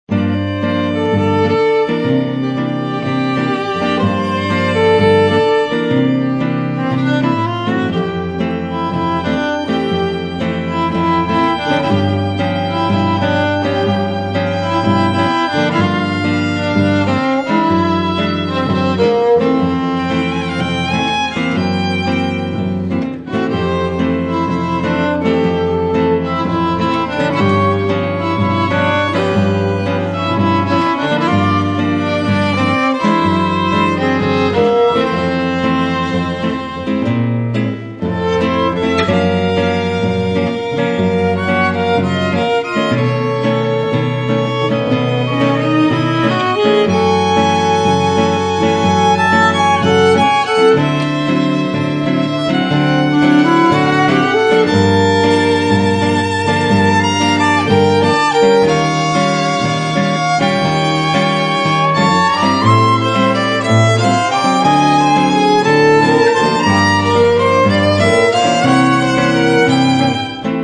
dvoje housle, violoncello, kytara